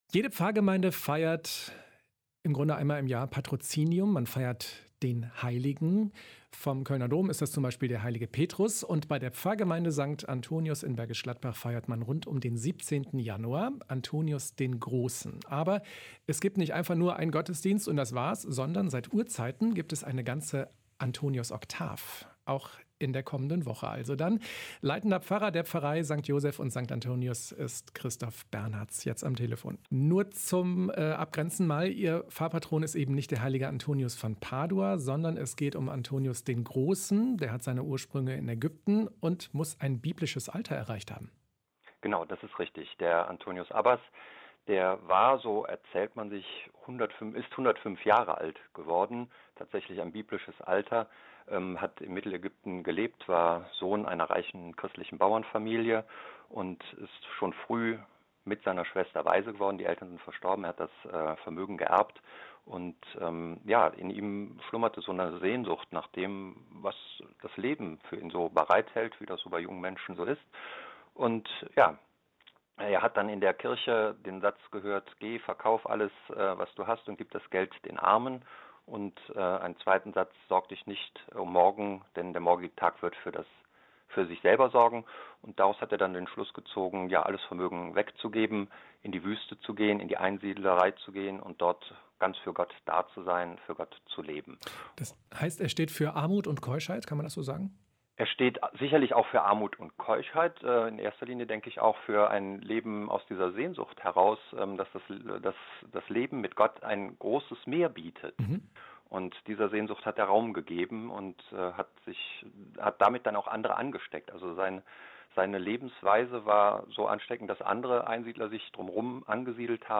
Ein Interview